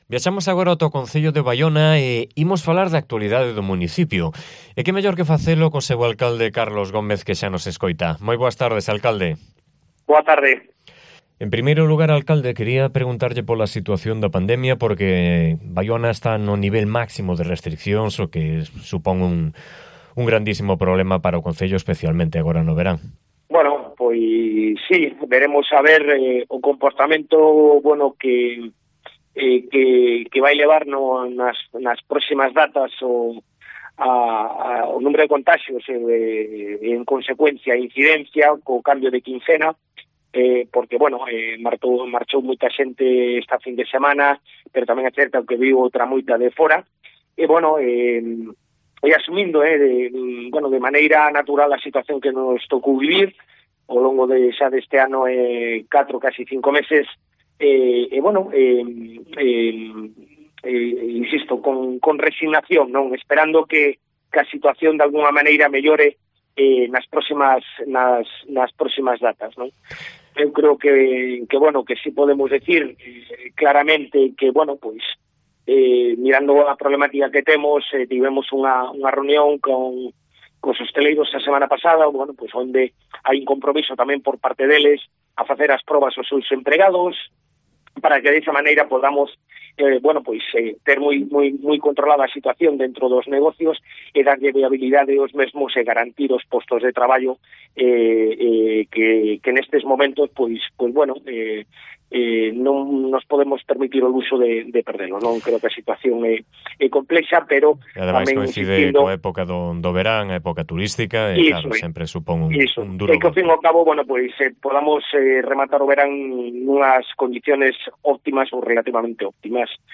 Entrevista con Carlos Gómez, alcalde de Baiona